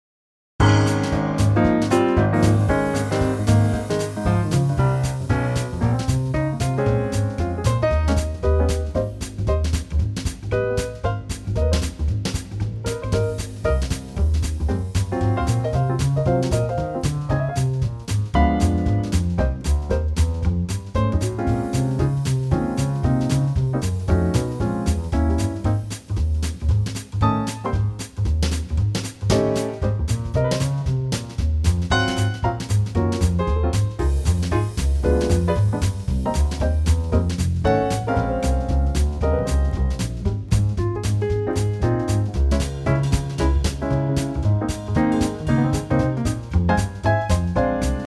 key - Ab - vocal range - Eb to Ab
Bright 2025 arrangement